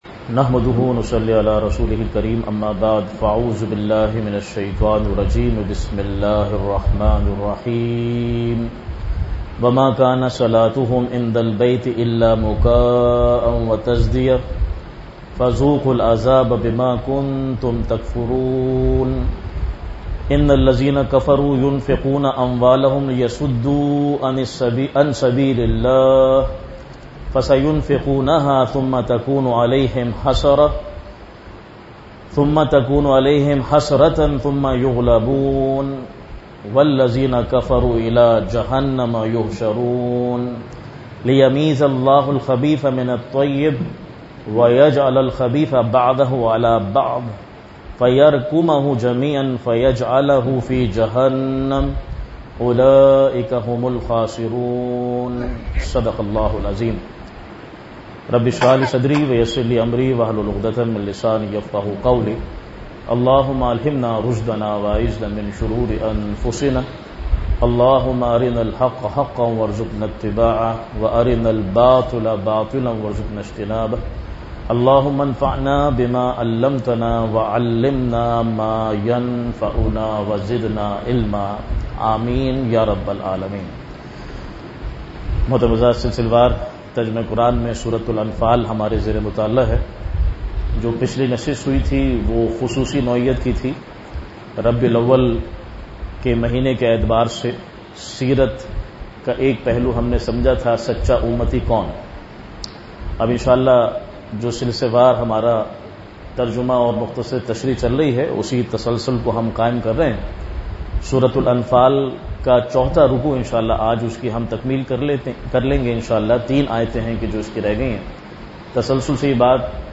Weekly Dars-e-Quran